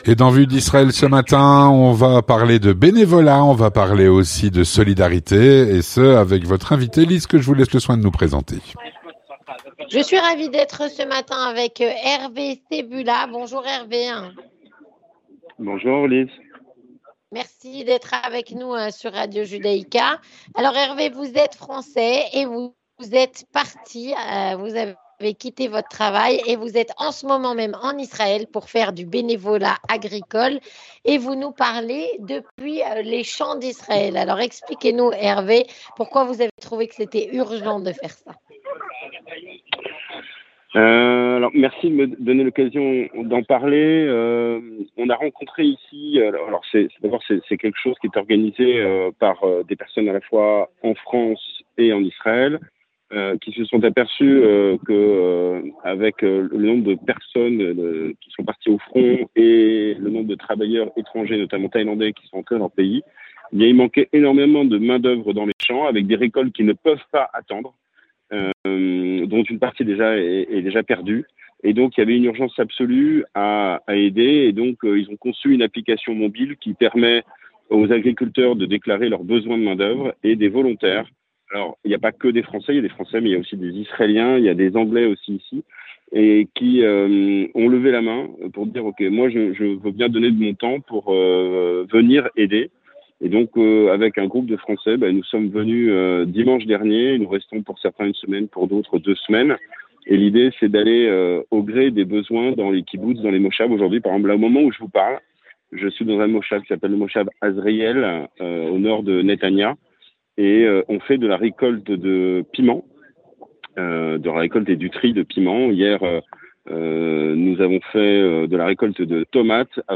Un volontaire Français est parti en Israël pour participer à une mission de solidarité qui propose de faire du bénévolat agricole . On en parle avec lui.